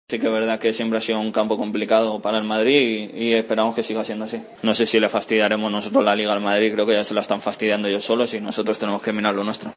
El jugador del Levante habló de las opciones del equipo granota frente al Real Madrid: "Creo que el equipo en casa están dando la cara y se esta dejando lo que se tiene que dejar, creo que no sé si le fastidiaremos la liga al madrid, ya se la están fastidiando ellos solos y nosotros tenemos que mirar lo nuestro".